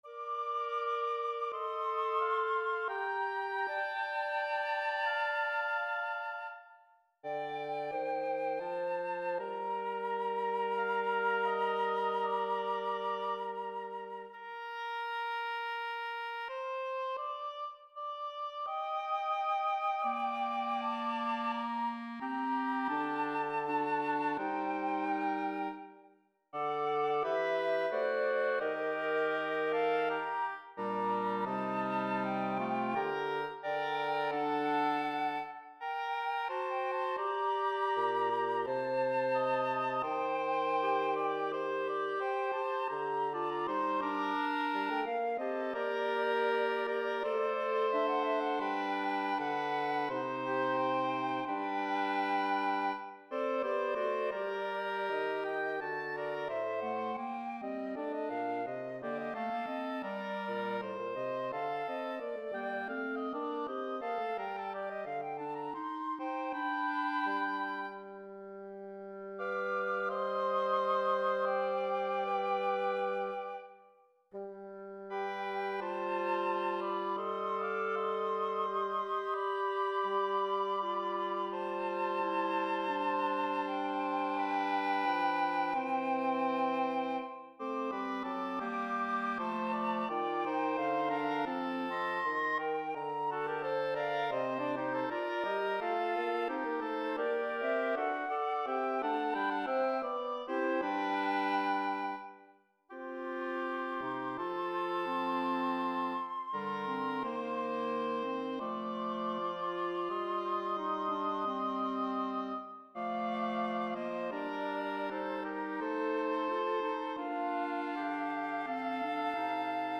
Bassoon, Clarinet, Flute Solo, Oboe
Voicing/Instrumentation: Bassoon , Clarinet , Flute Solo , Oboe